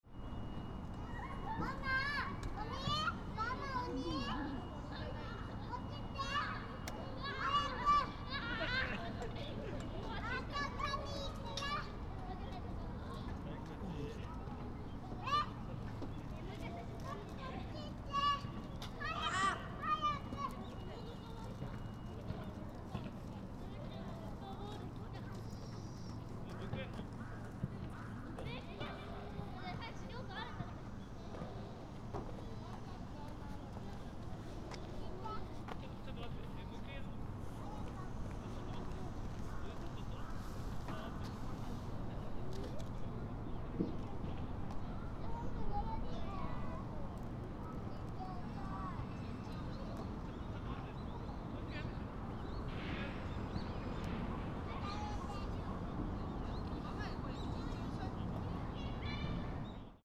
In the warm sunshine in the winter, some children's groups and several children with their parents played cheerfully. ♦ Some crows were cawing, and some birds were twittering.